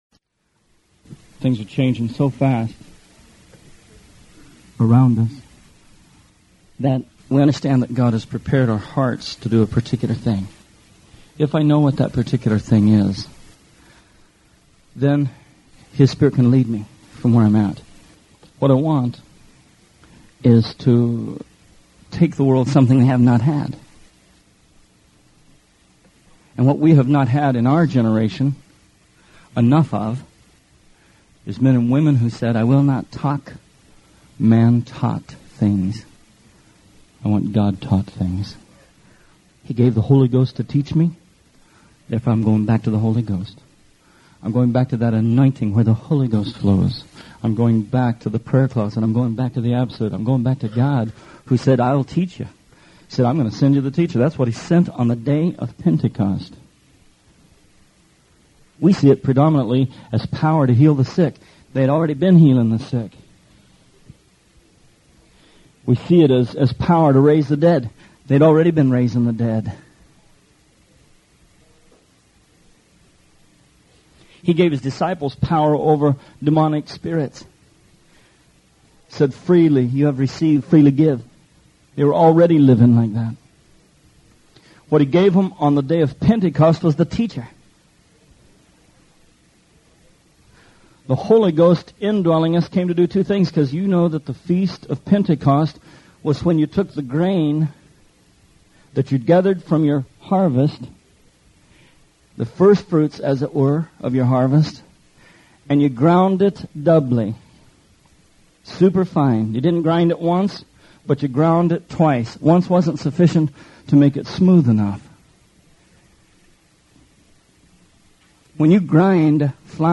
Songs of Degrees: This is a sermon series about Psalms 120 through 134. The Songs of Degrees, or the Songs of Ascents, are meant to teach us about our upward journey toward what God wills for His church.